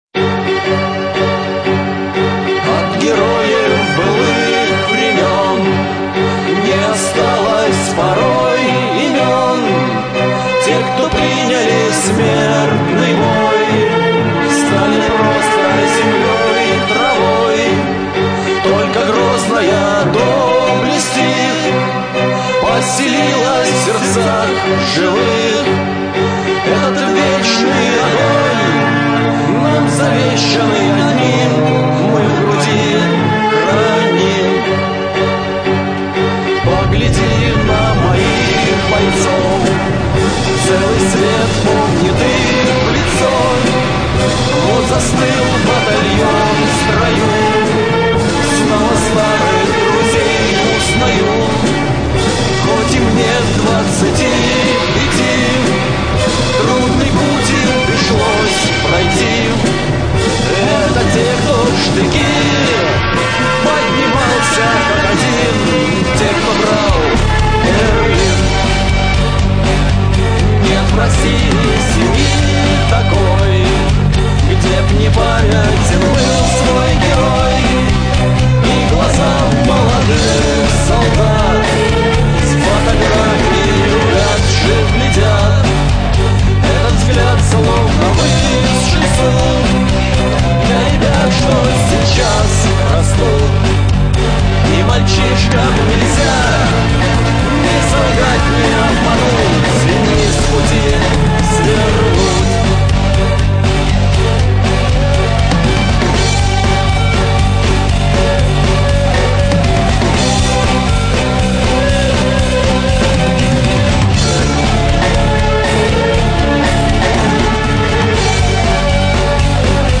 Жанр: Рок Инструментальная музыка